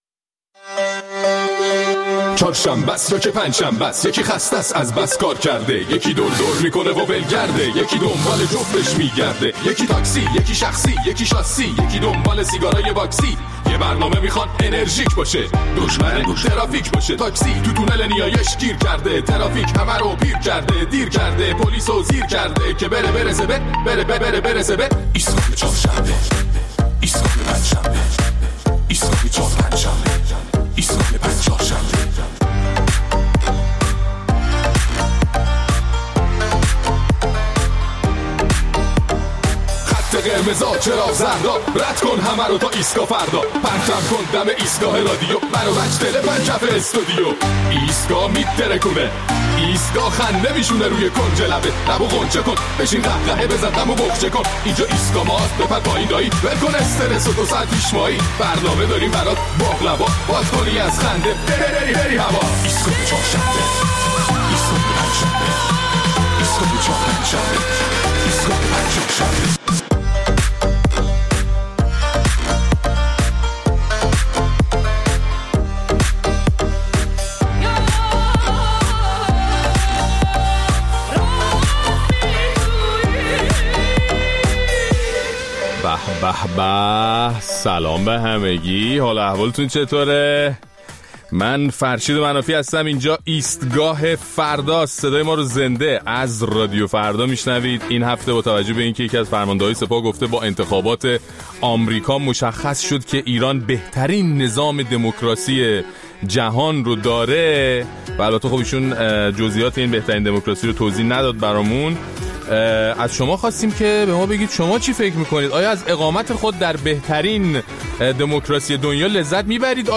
در این برنامه ادامه نظرات شنوندگان ایستگاه فردا را در مورد دموکراسی در جمهوری اسلامی و مقایسه آن با دموکراسی آمریکا توسط مقامات نظام می‌شنویم.